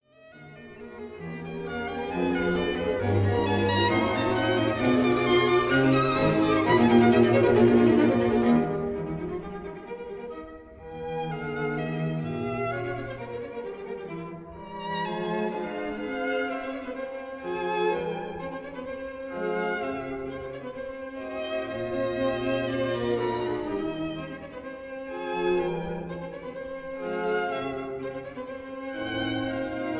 violins
violas
cellos